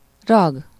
Ääntäminen
Ääntäminen US noun: IPA : /ˈsʌfɪks/ verb: IPA : /ˈsʌfɪks/ IPA : /səˈfɪks/